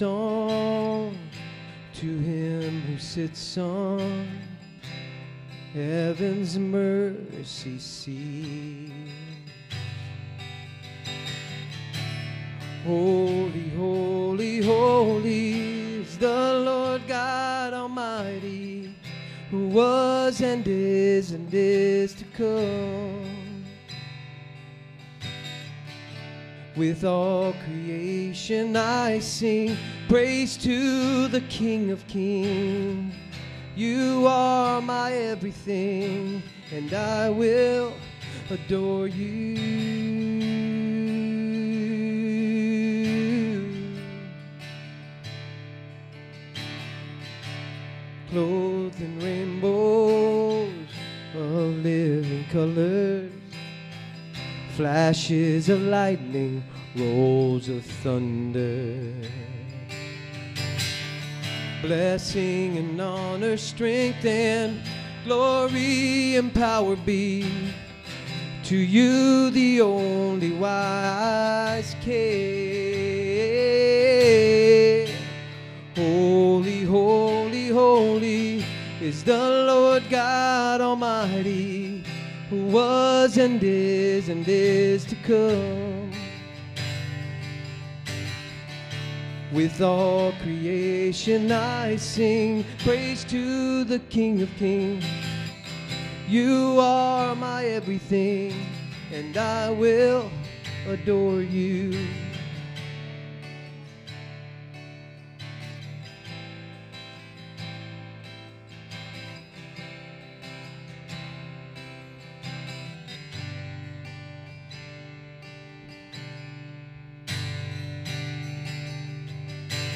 SERMON DESCRIPTION Through God’s grace, all our sins are forgiven except one.